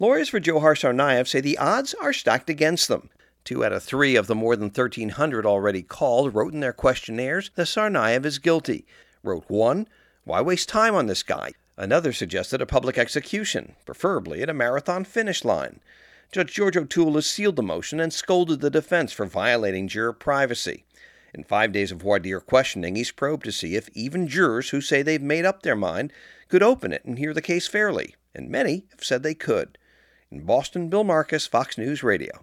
FROM BOSTON.